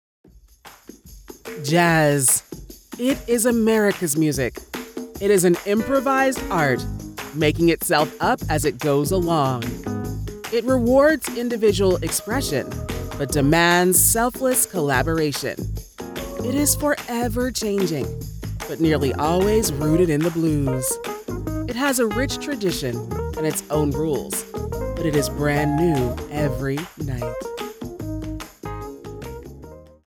Best Female Voice Over Actors In March 2026
All our voice actors are premium seasoned professionals.
Yng Adult (18-29) | Adult (30-50)